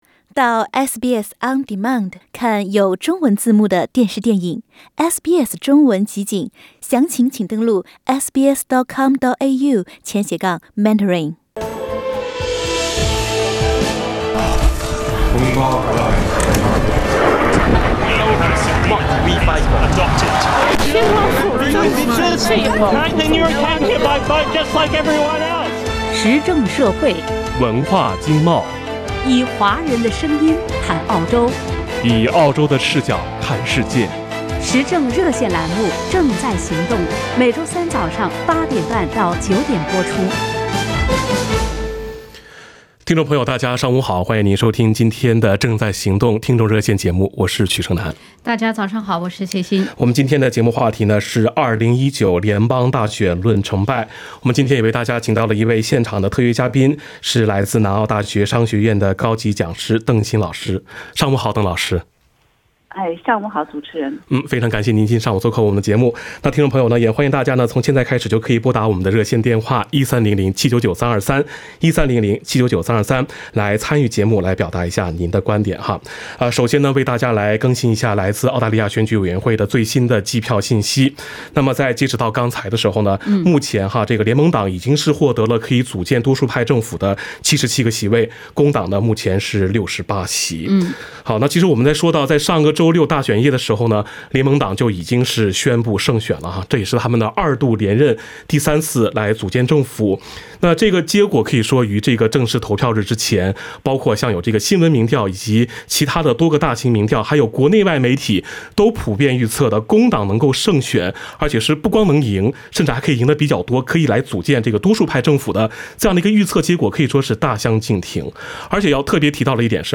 现场特约嘉宾